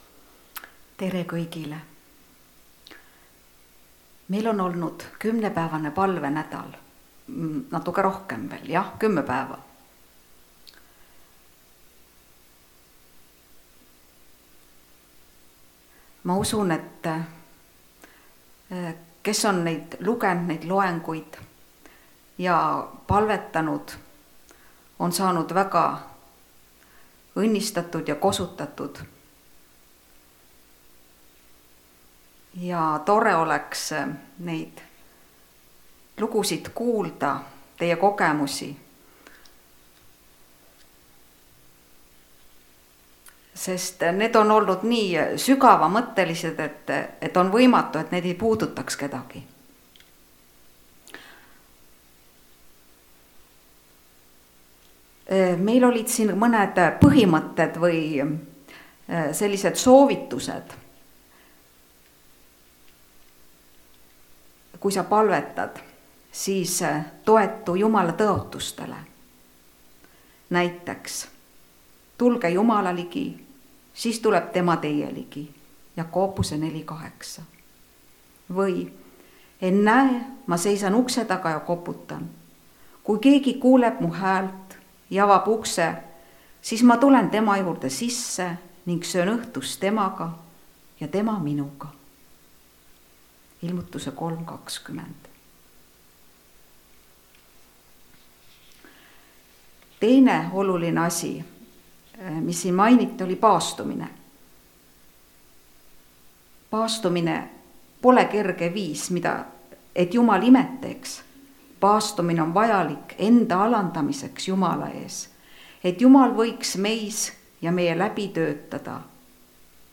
Ära saada meid kiusatusse (Rakveres)
Jutlused